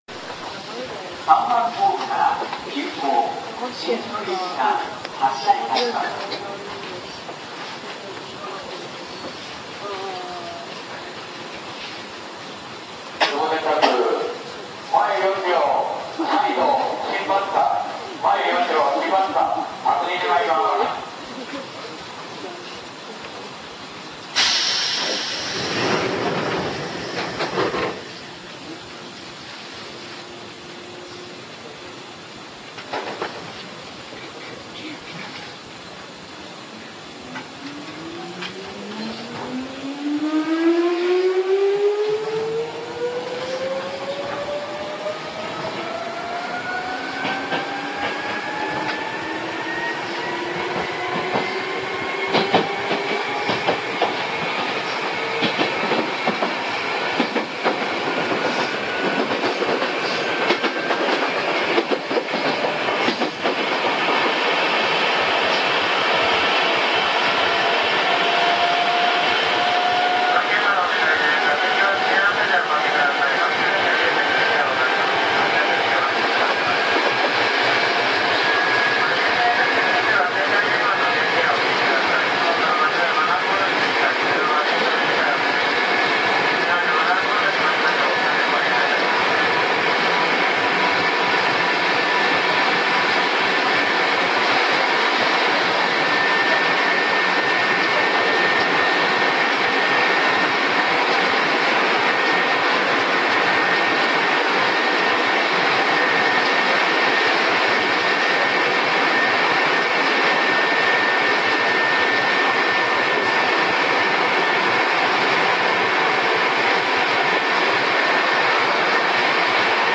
そして出発すると今度は凄いうなりがします。
そしてスピードが出てくると、今度は会話もままならないほどの爆音を発します。国鉄車両のような低い音ではなく非常に高い音がメインなのですが、暫く聞いてるともうわけがわからなくなります(^^;;
ちなみに冒頭で業務連絡が入ってますが、前4両の8000形が新宿行きなのに新松田の幕を出してたんですね。
小田急線　伊勢原〜愛甲石田（2720）